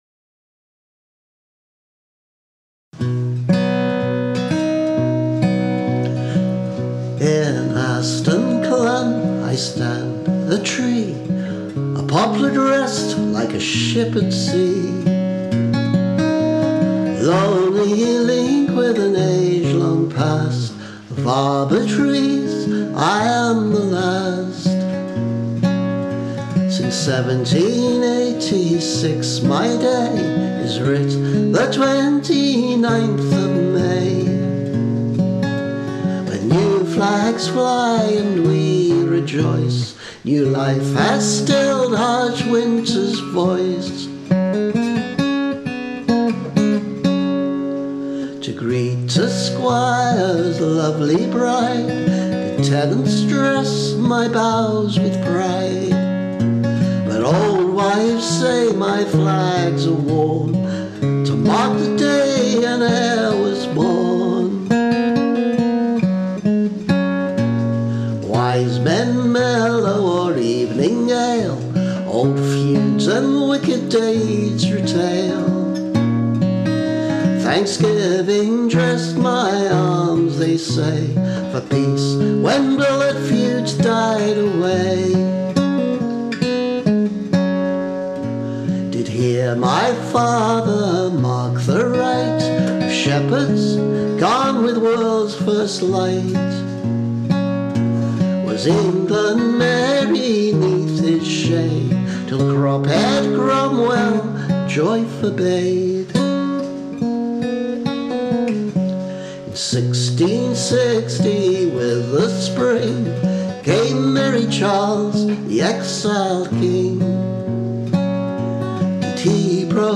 This is the second demo version: still rough, but now with some basic guitar.
This version of the tune is one of my ‘make it up as you go along’ recordings: it may well change significantly over time, and is not in any case consistent between all the verses.